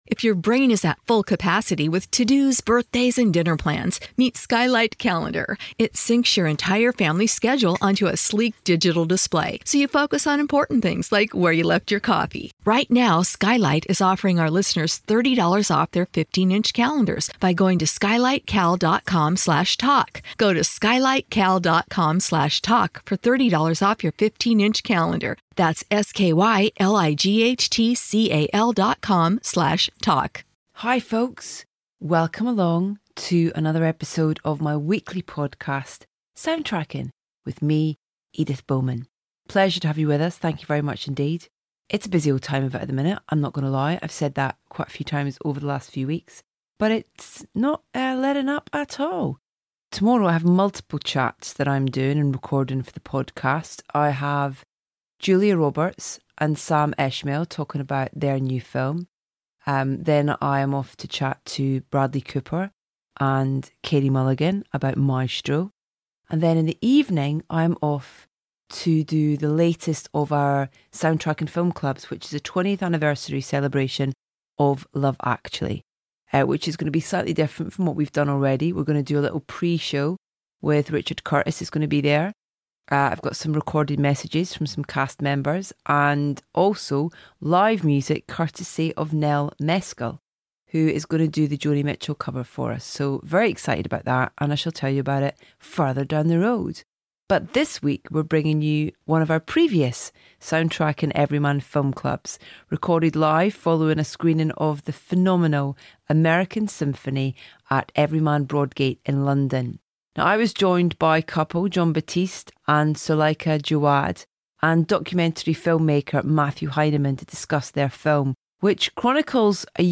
We're bringing you another of our Soundtracking Everyman Film Clubs today, recorded live following a screening of American Symphony in London.